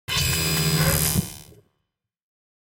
جلوه های صوتی
دانلود صدای ربات 74 از ساعد نیوز با لینک مستقیم و کیفیت بالا